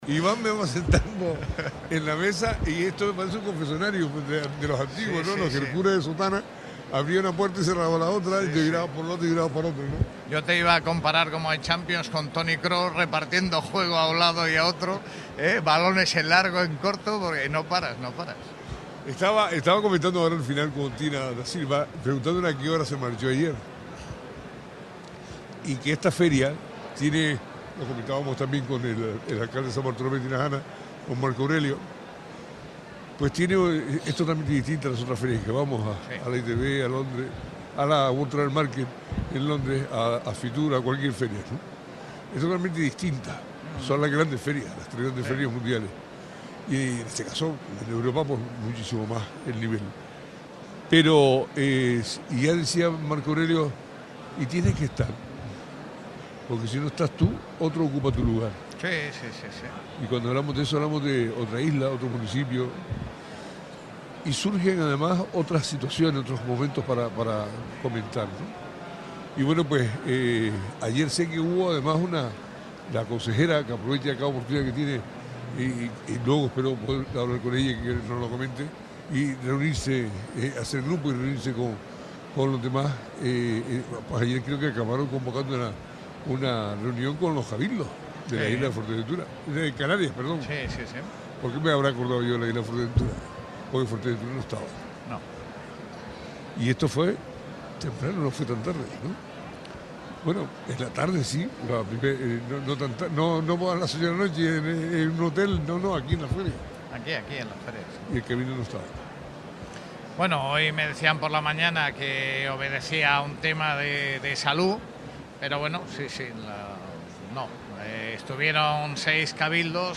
Miguel Ángel Rodríguez, director general de Ordenación, Formación y Promoción Turística de la Consejería de Turismo y Empleo del Gobierno de Canarias intervino esta mañana en el especial que Radio Sintonía realiza desde la Bolsa Internacional de Turismo de Berlin donde expuso que a las ferias se va a promocionar y también a explicar.